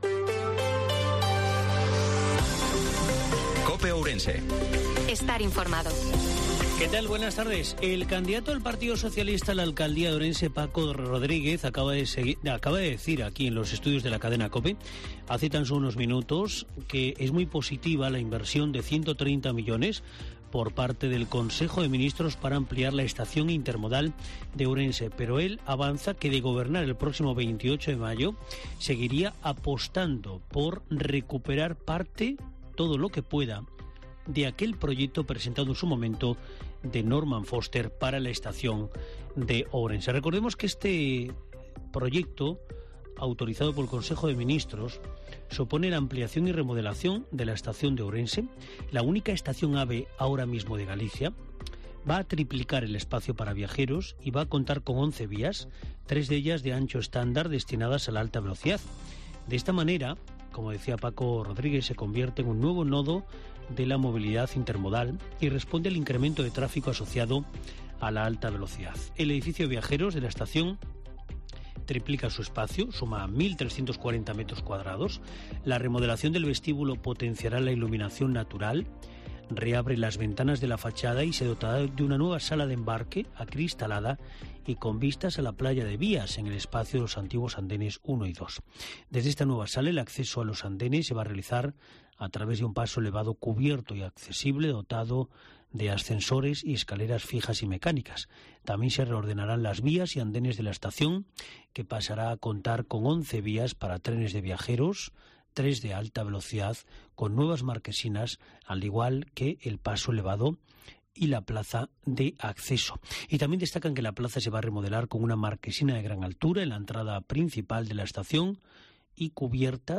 INFORMATIVO MEDIODIA COPE OURENSE-18/05/2023